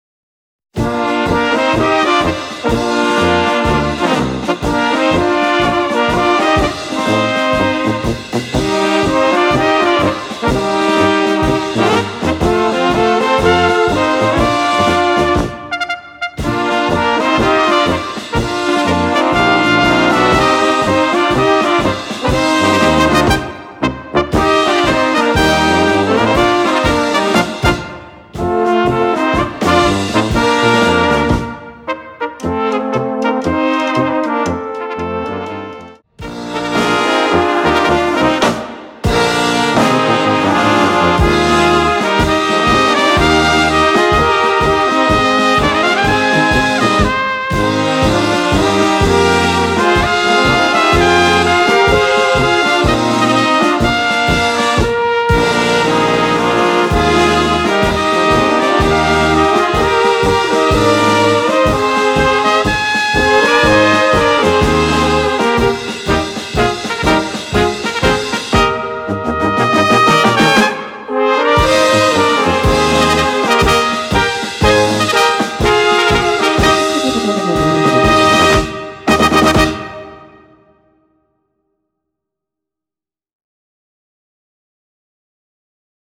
Physisch und digital für Kleine Besetzung erhältlich.